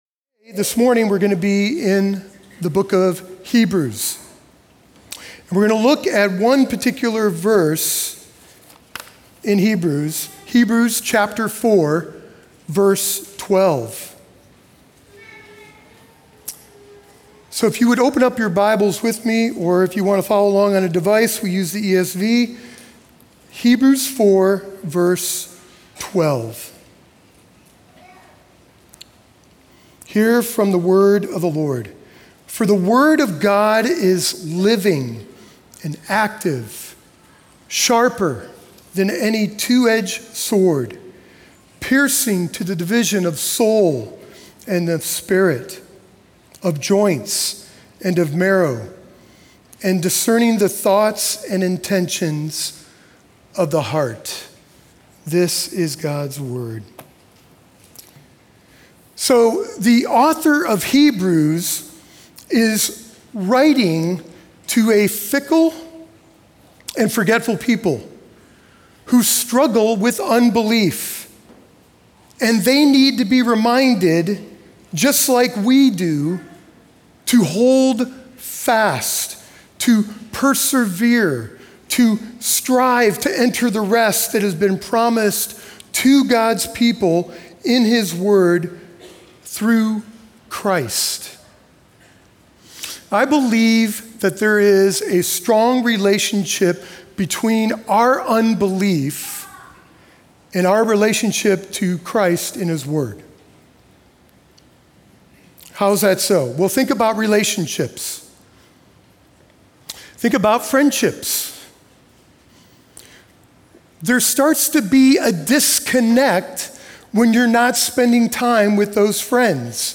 Sunday Worship | Substance Church, Ashland, Ohio
Sermons